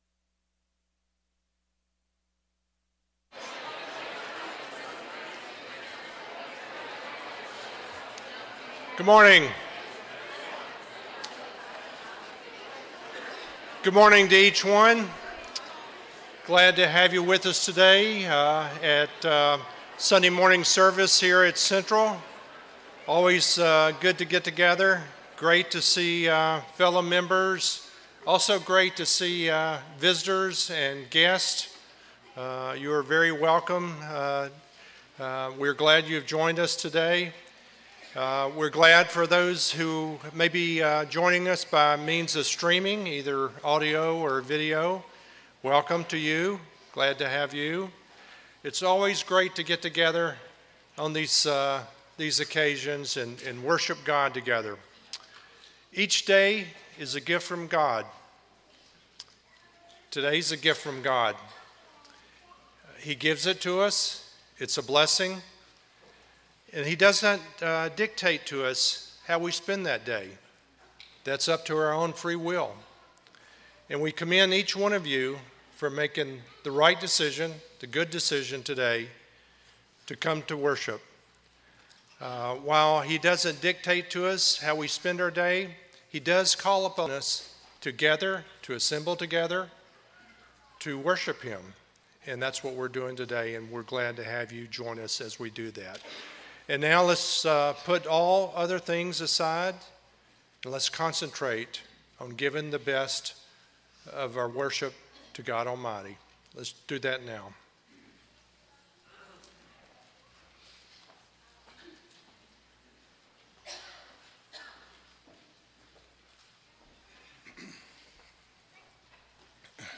Series: Sunday AM Service